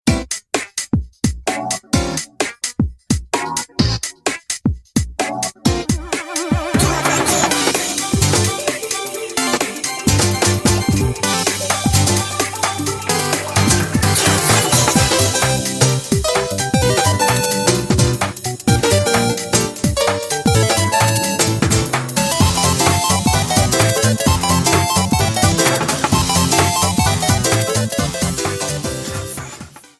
Trimmed and fadeout
Fair use music sample